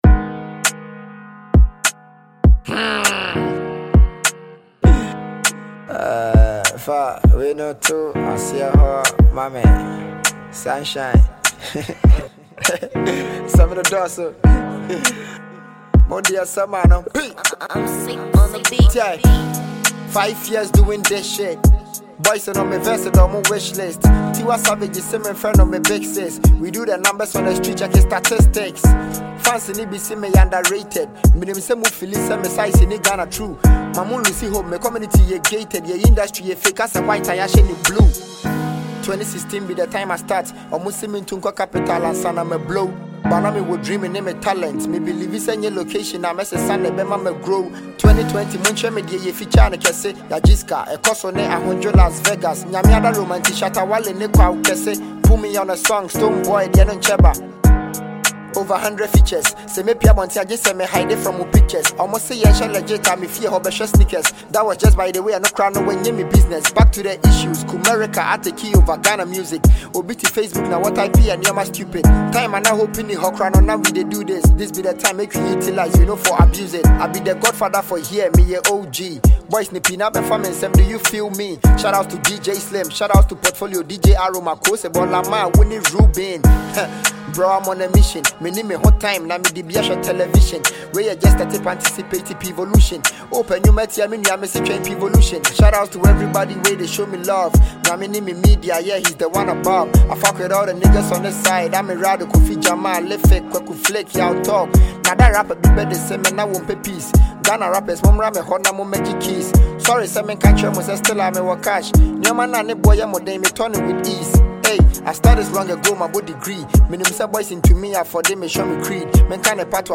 Ghanaian hip hop recording artist